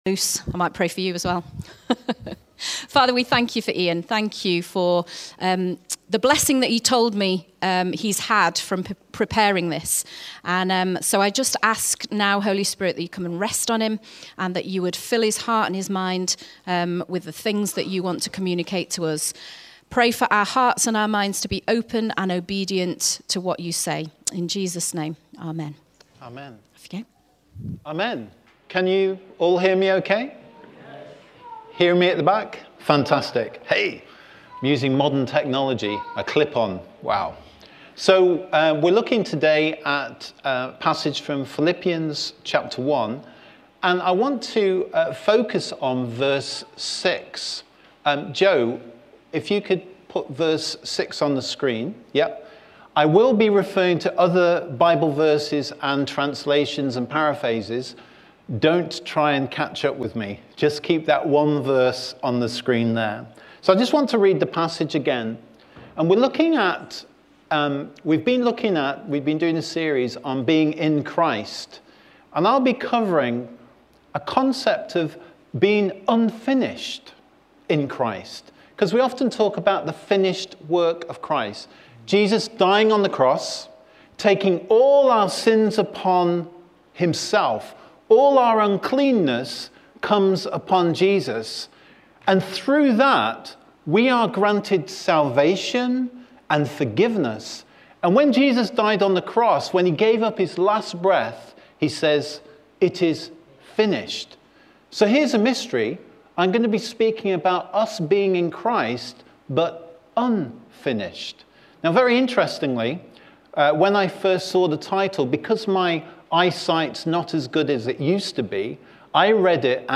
A talk from the series "Our Identity in Christ."